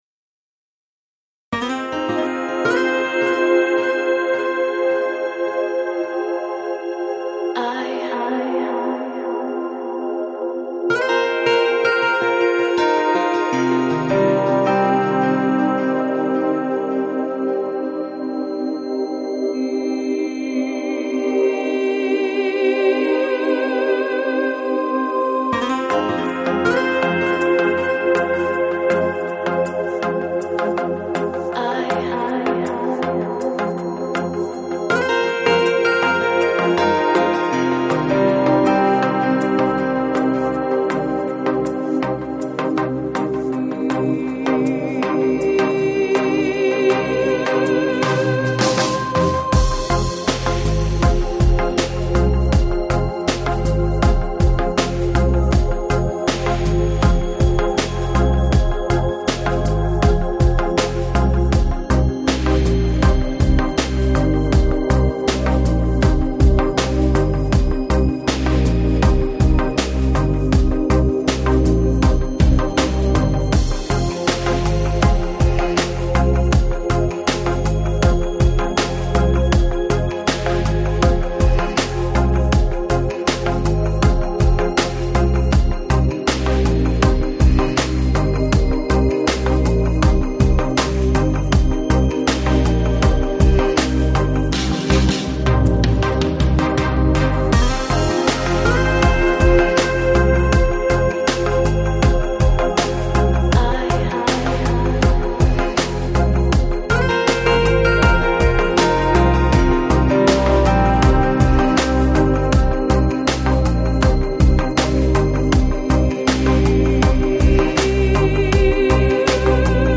Electronic, Downtempo, Lounge, Chill Out, Balearic